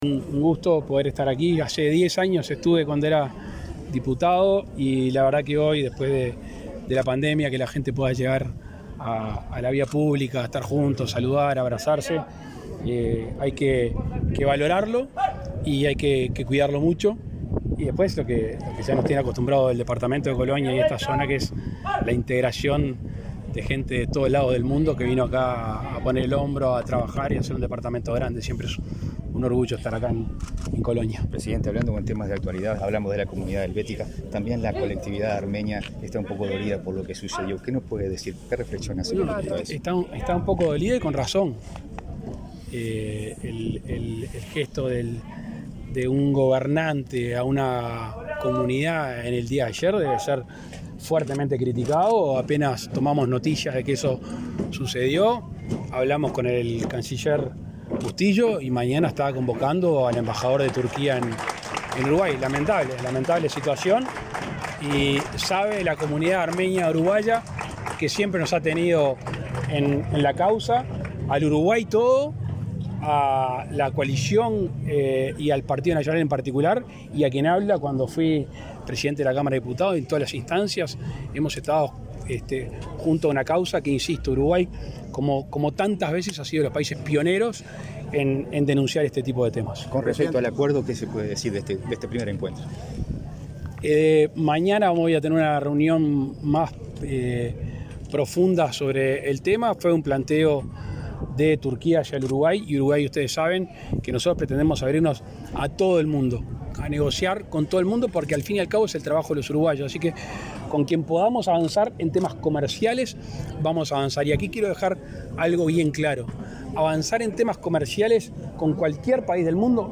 Declaraciones del presidente Lacalle Pou a la prensa
El presidente Luis Lacalle Pou participó este domingo 24 del acto de celebración de los 160 años de la fundación de Nueva Helvecia y, luego, dialogó